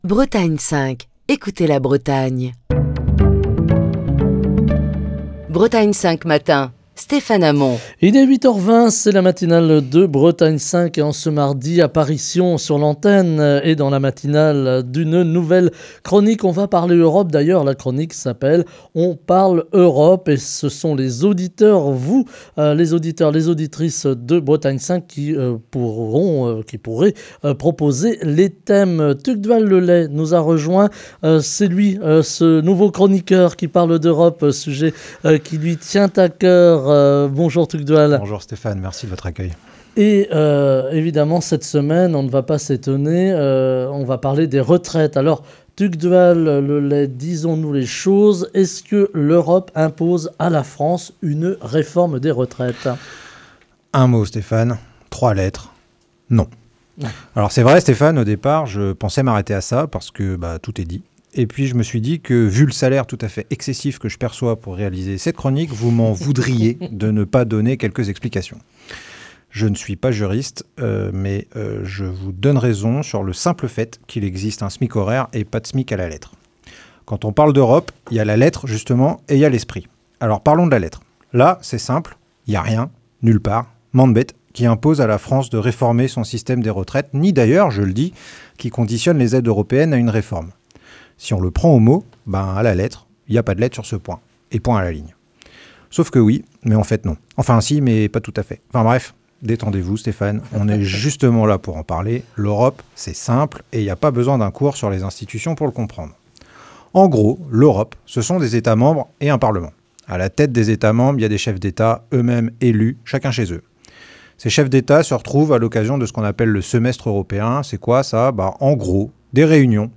Chronique du 17 janvier 2023. L'Europe impose t-elle une réforme des retraites à la France ?